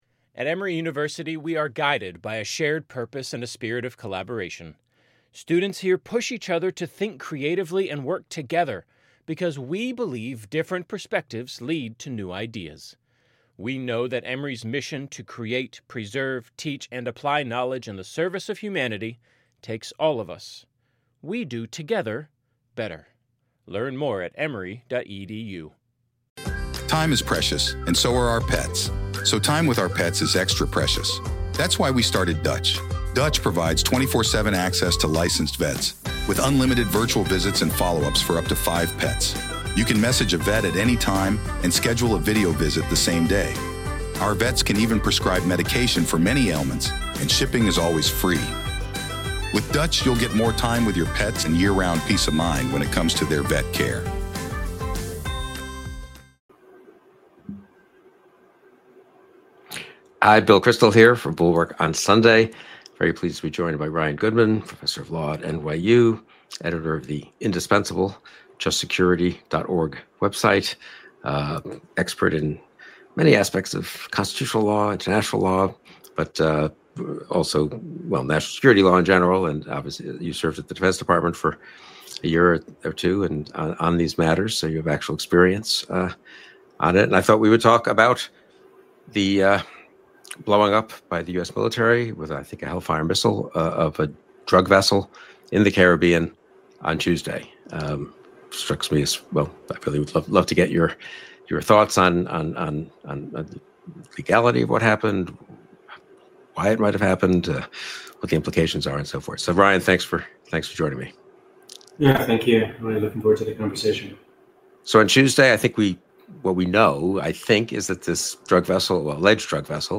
NOTE: The guest had some technical issues with his audio.